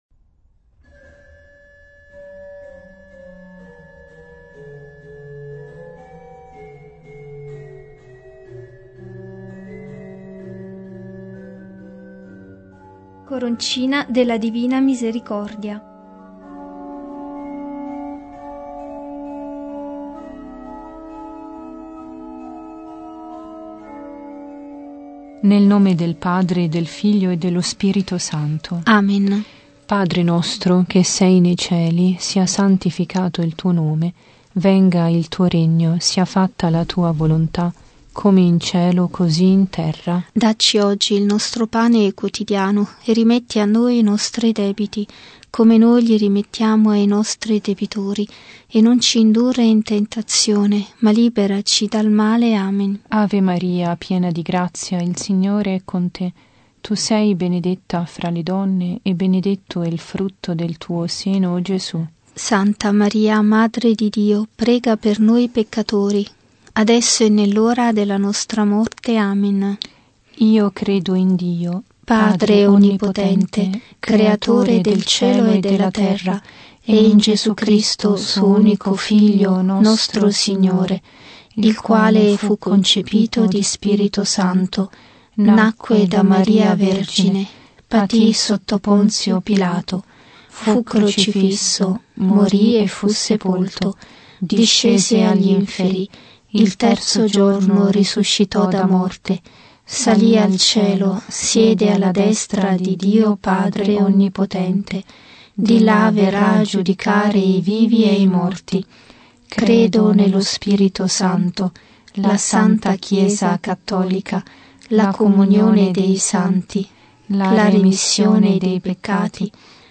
Genere: Coroncine.